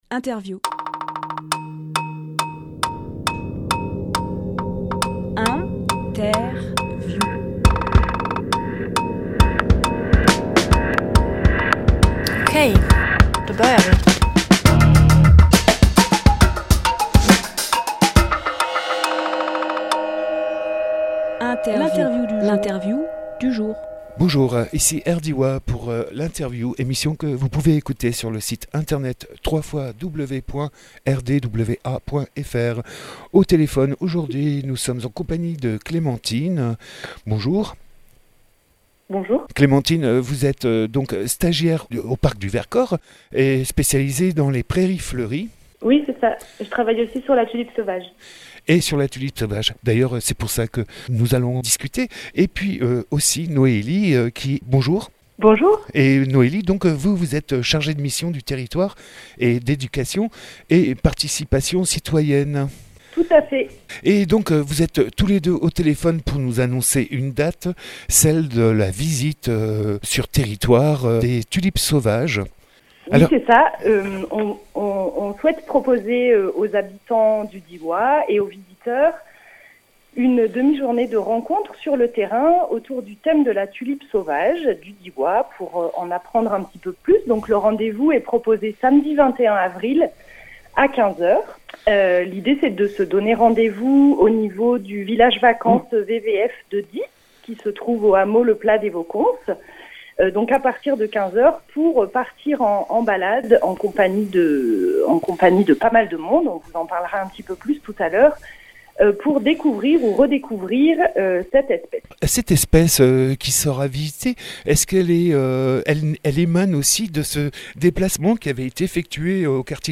Emission - Interview A la rencontre des tulipes sauvages du Diois Publié le 17 avril 2018 Partager sur…
Lieu : Studio RDWA